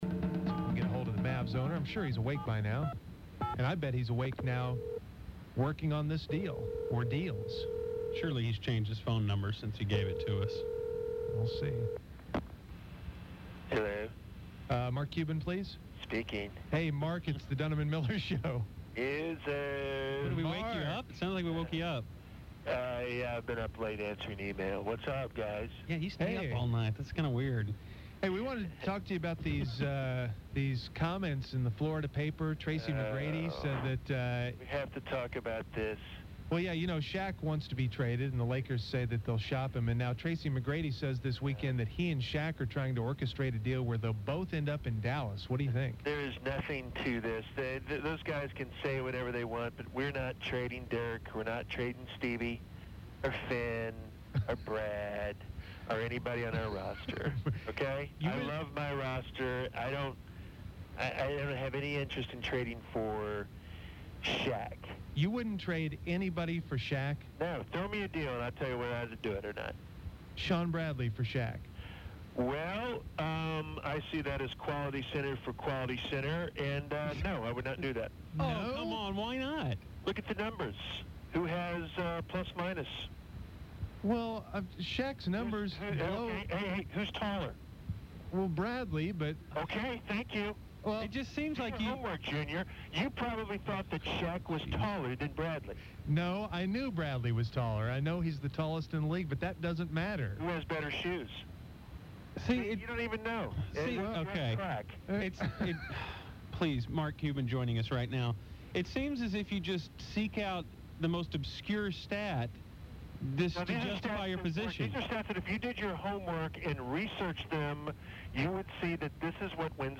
The Musers speak to Mavericks owner Mark Cuban about who he would trade to get Shaq